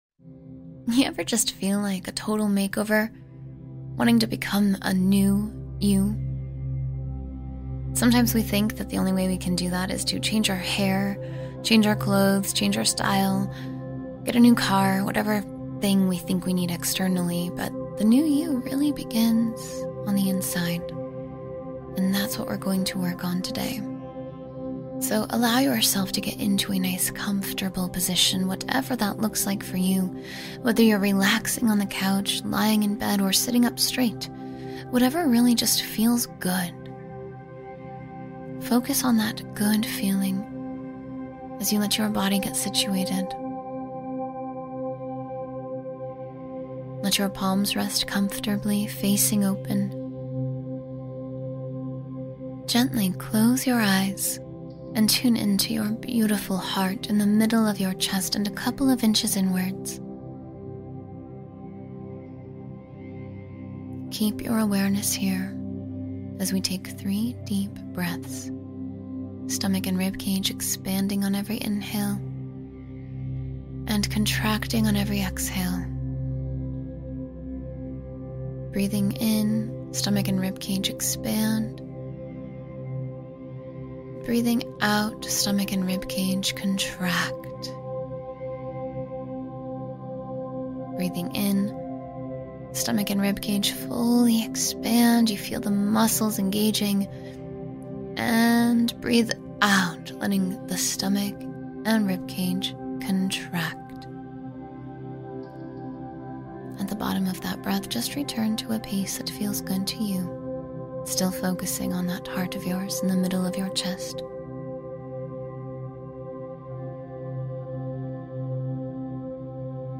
Becoming a New You — 10-Minute Guided Meditation for Transformation